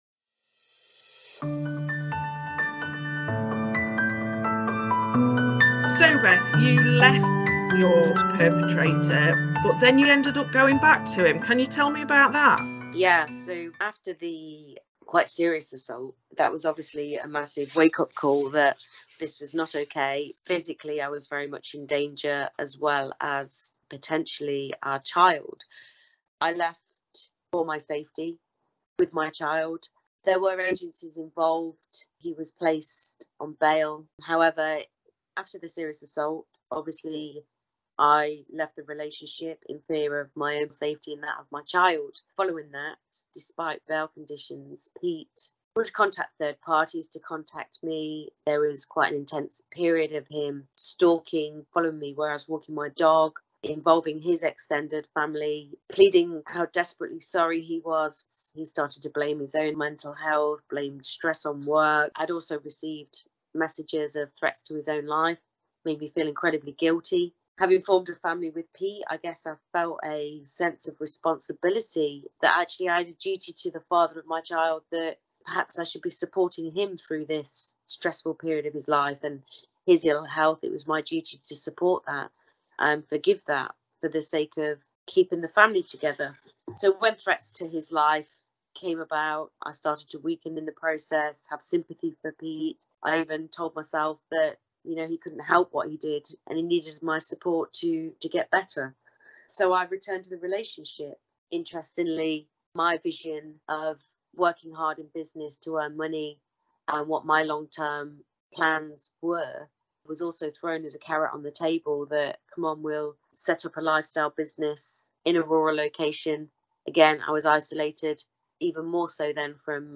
Interview with a victim episode 5